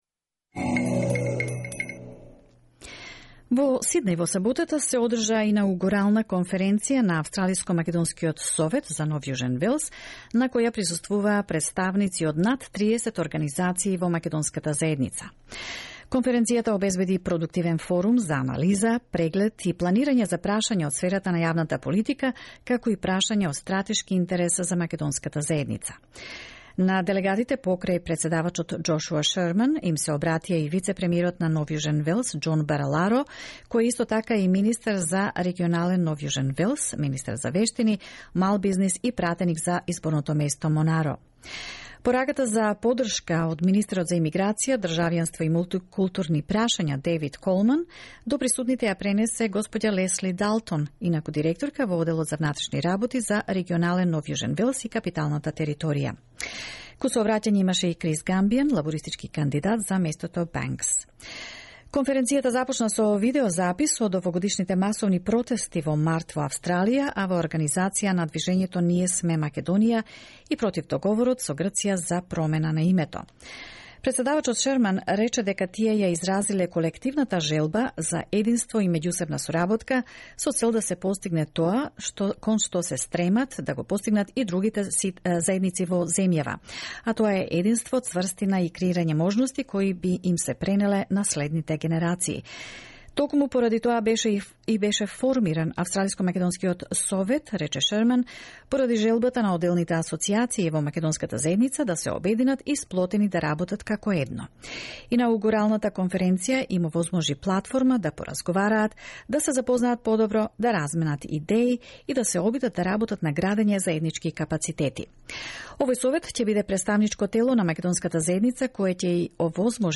The Inaugural Macedonian Community Leaders Conference was held on 8th December in Sydney, organised by the peak representative body of the Macedonian Community in NSW, Australian-Macedonian Council of New South Wales that represents collective Macedonian-Australian perspective regarding maters of public policy and strategic importance